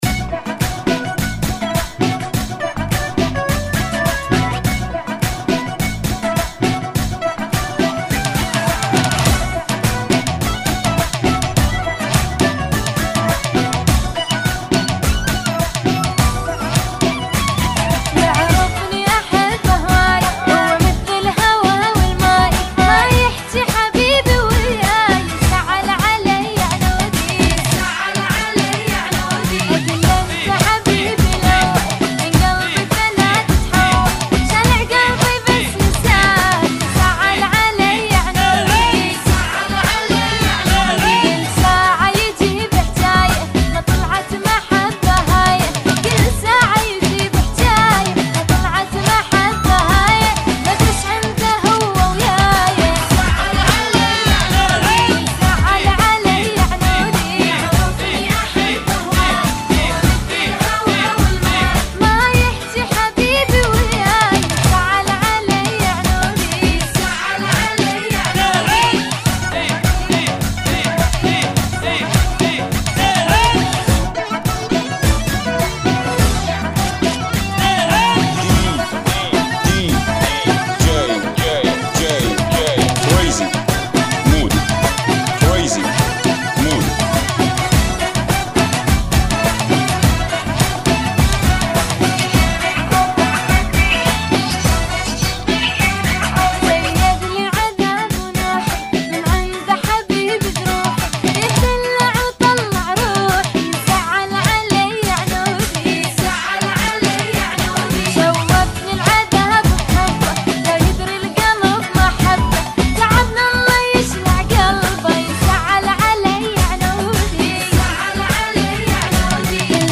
Funky Mix [ 104 Bpm ]